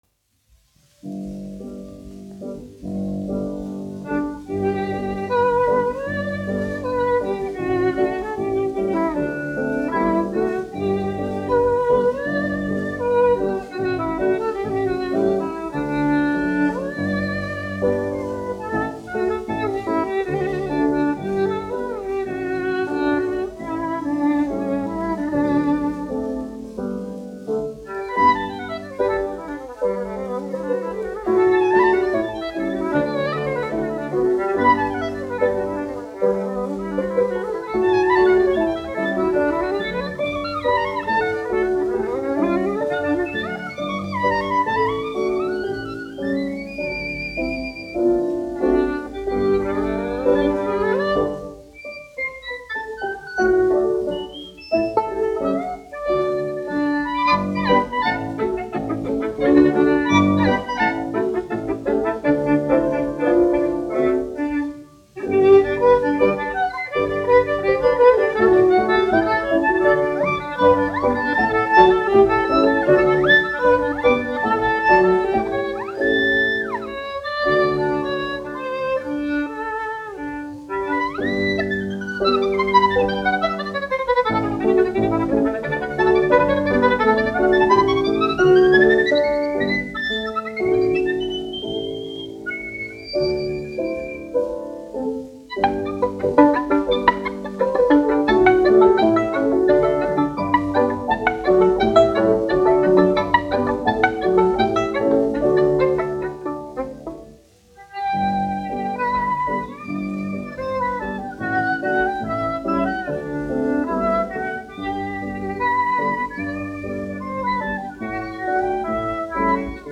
1 skpl. : analogs, 78 apgr/min, mono ; 25 cm
Vijoles un klavieru mūzika
Skaņuplate
Latvijas vēsturiskie šellaka skaņuplašu ieraksti (Kolekcija)